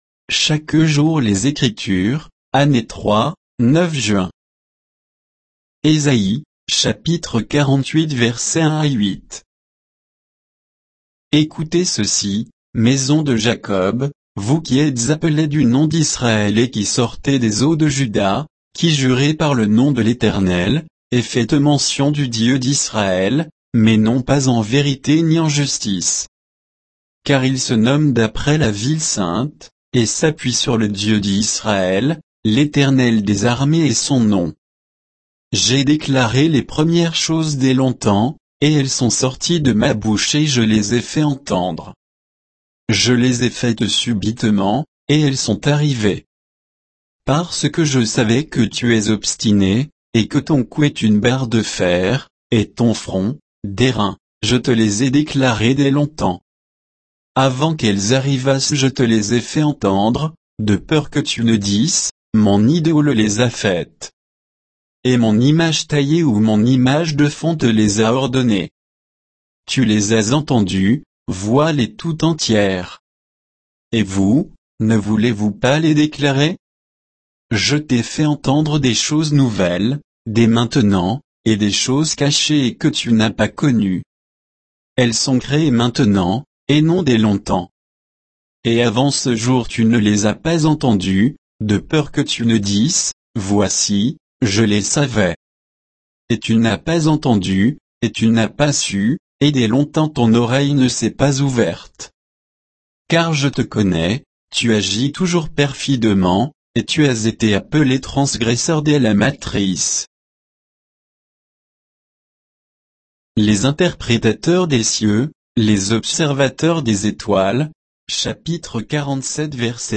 Méditation quoditienne de Chaque jour les Écritures sur Ésaïe 48